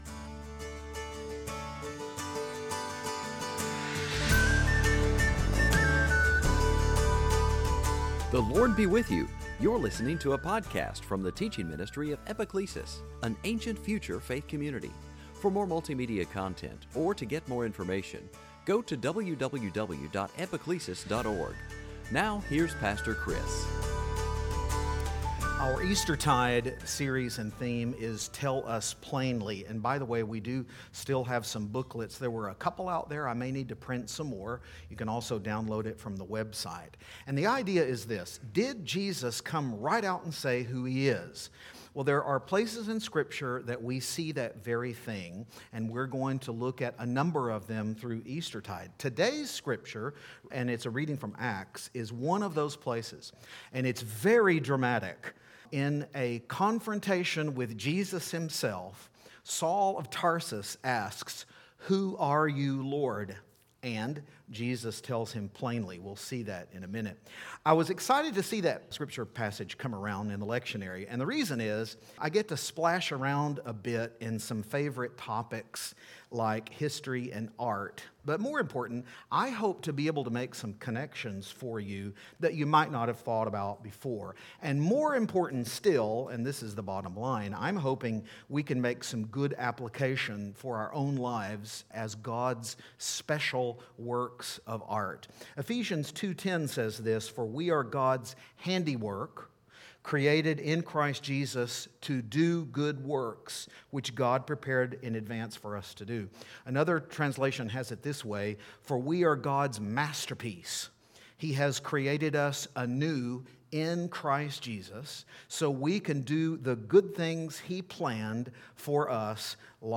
In the sermon